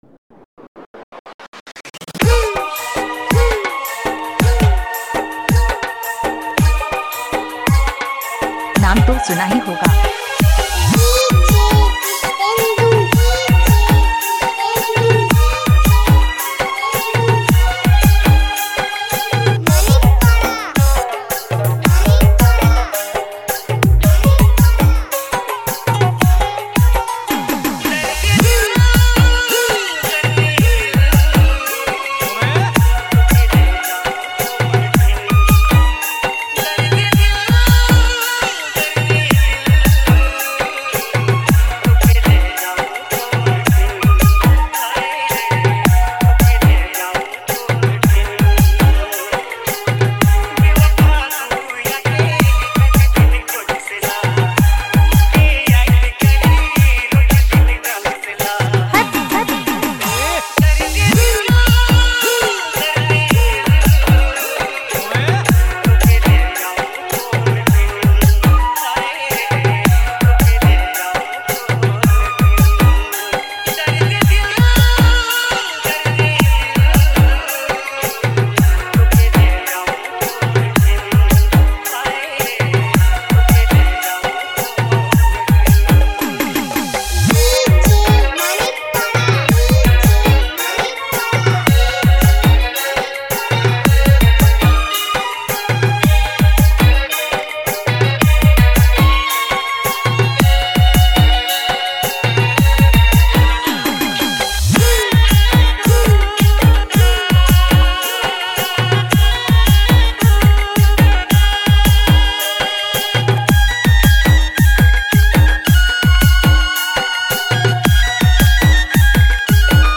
touching yet lively Nagpuri remix